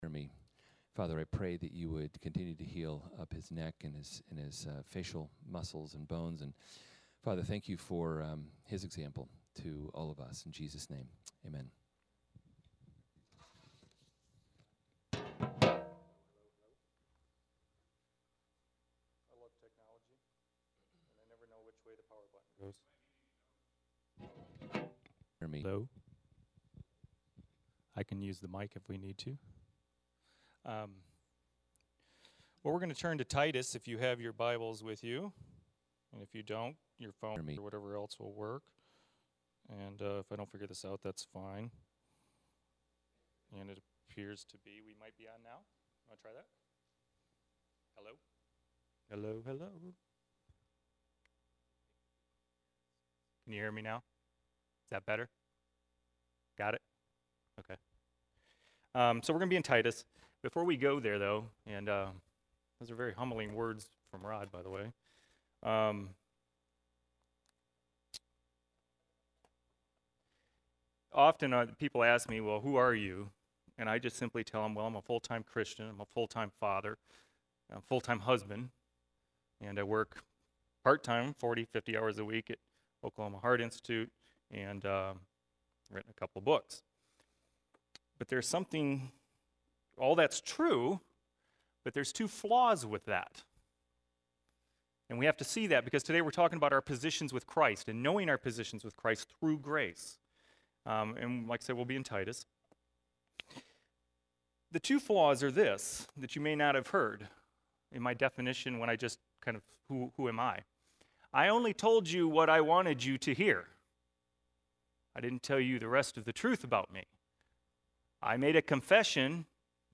Men’s Meeting Bartlesville April 25th, 2015
(the beginning of the message was not recorded) You can also read his message, Is There Not A Cause, and contact him here.